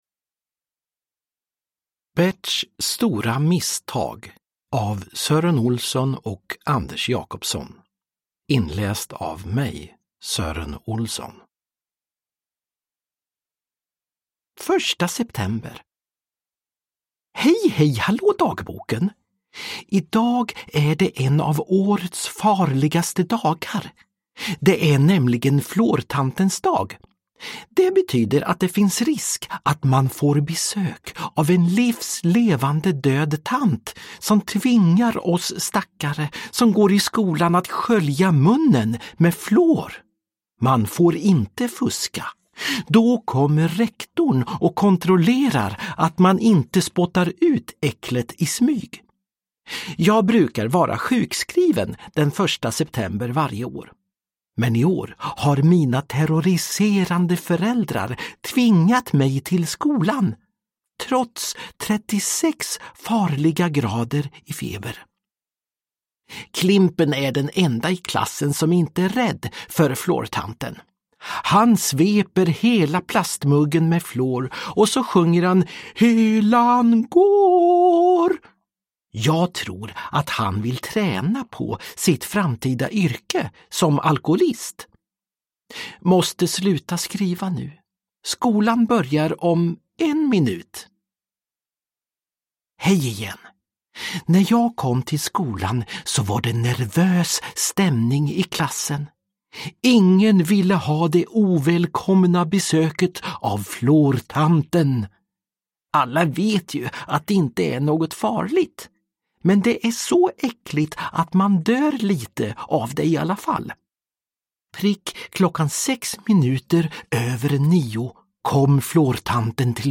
Berts stora misstag – Ljudbok
Uppläsare: Sören Olsson